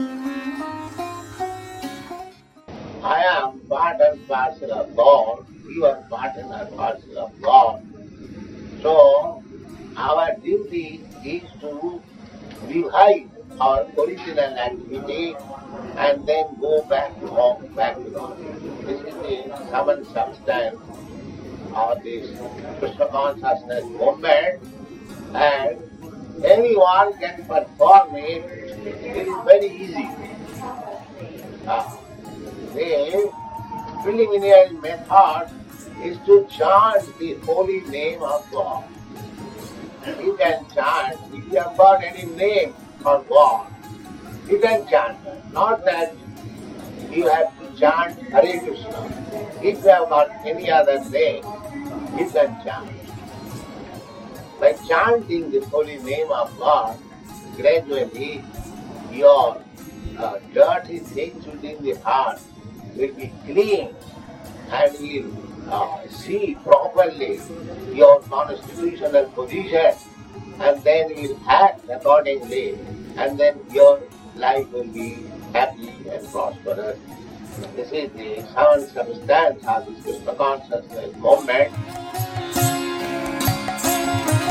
(670208 – Lecture CC Adi 07.69-75 – San Francisco)